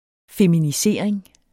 Udtale [ feminiˈseˀɐ̯eŋ ]